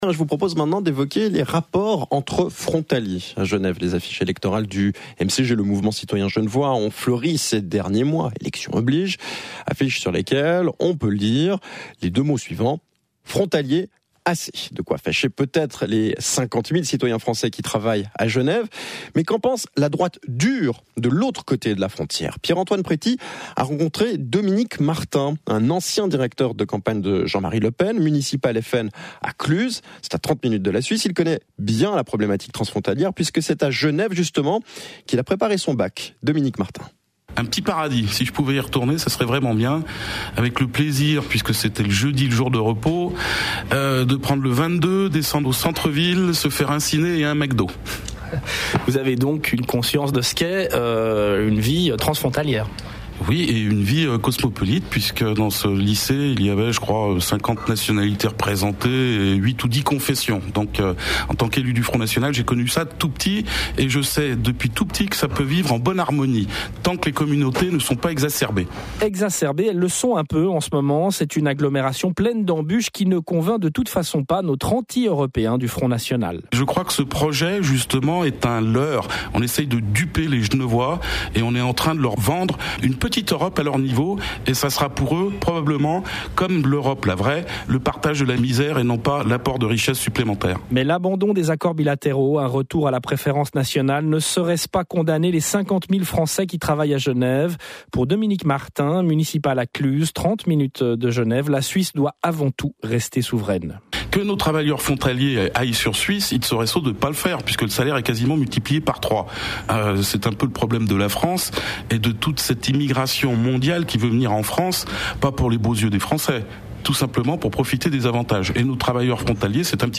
Le 25 octobre dernier, notre élu Dominique Martin a été l’invité du journal du matin de la Radio Suisse Romande. Le sujet : les frontaliers.